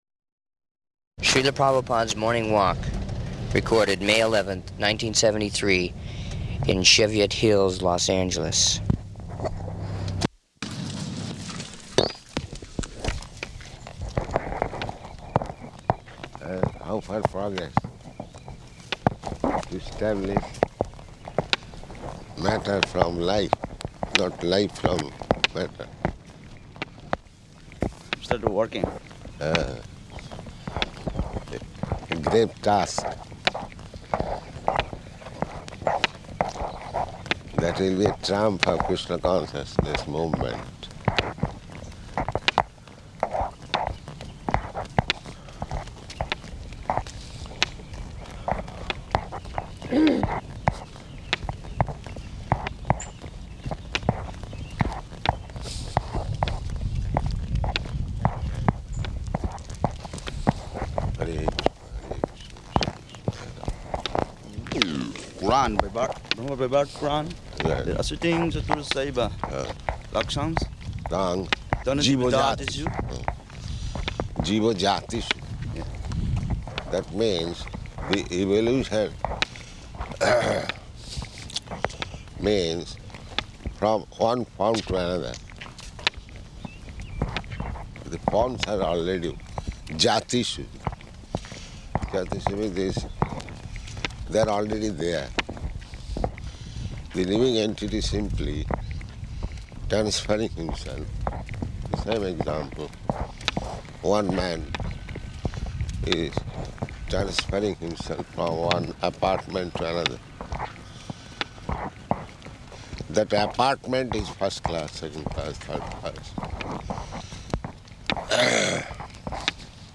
-- Type: Walk Dated
Śrīla Prabhupāda's morning walk, recorded May 11th, 1973, in Cheviot Hills, Los Angeles.